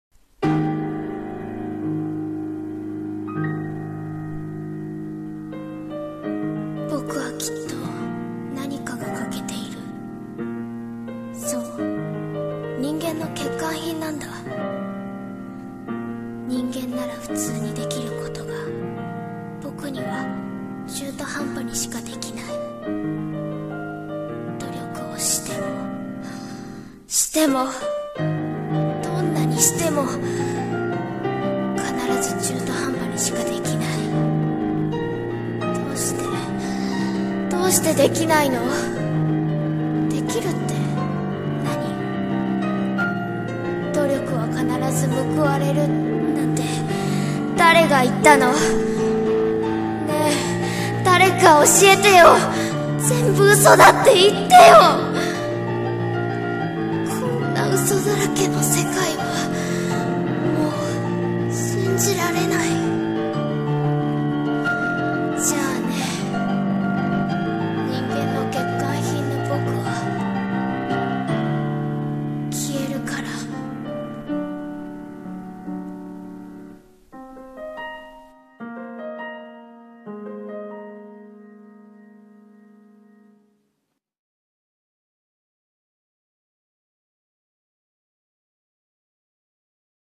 【朗読台本】人間の欠陥品の僕